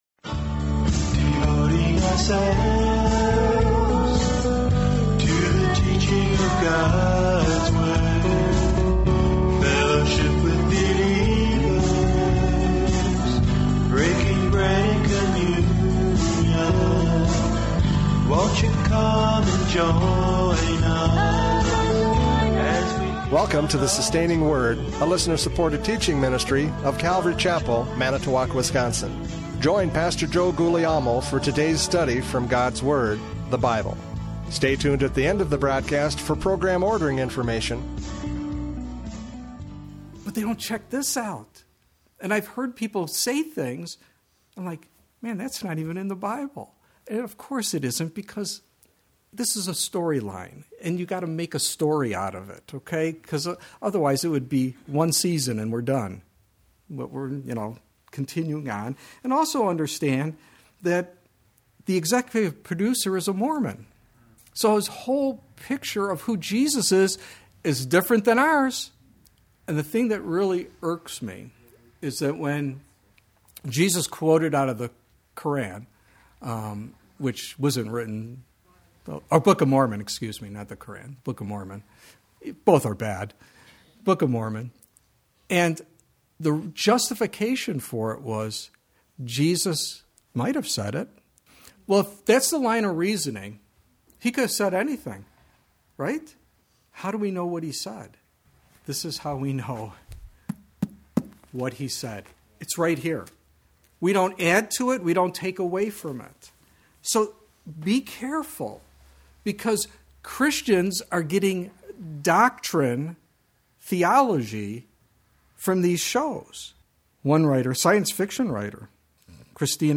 Radio Studies Service Type: Radio Programs « Prophecy Update 2023 “Oh My Gosh!”